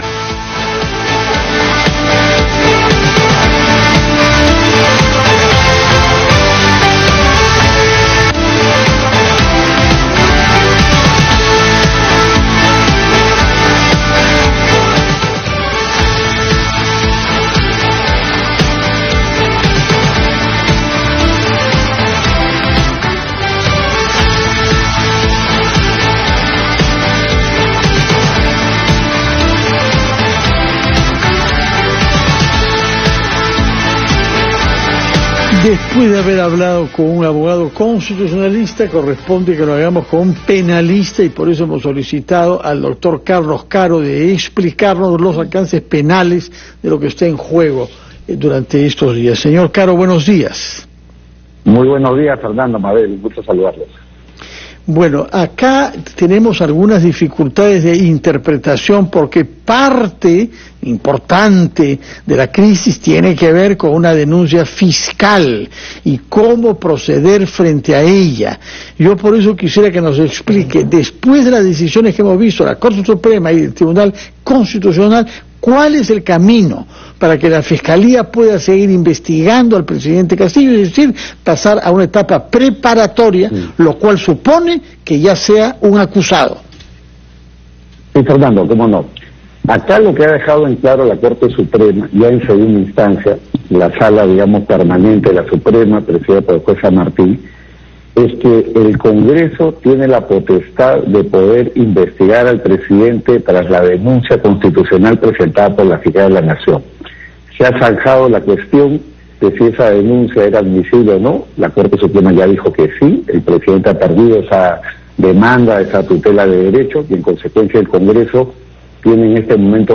Entrevista al abogado penalista